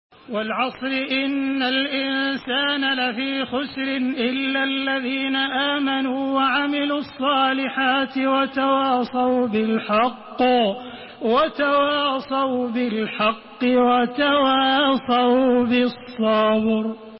Surah Asr MP3 by Makkah Taraweeh 1432 in Hafs An Asim narration.
Murattal